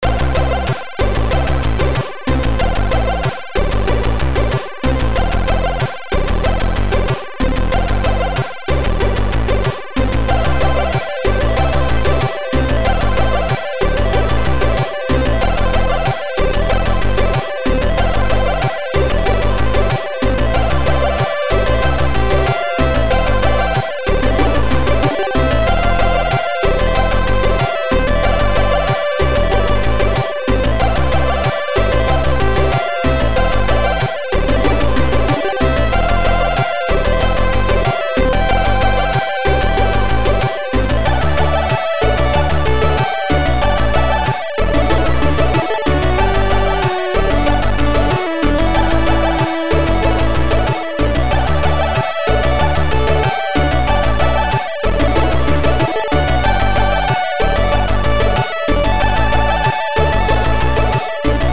AHX v2 Tracker